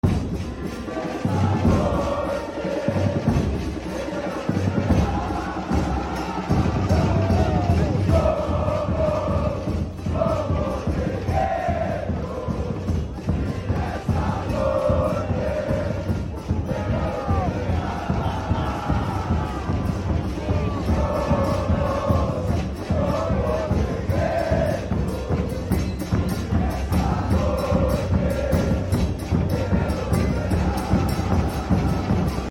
TORCIDA GRÊMIO X VASCO NA sound effects free download
TORCIDA GRÊMIO X VASCO NA ARENA CONDA EM CHAPECÓ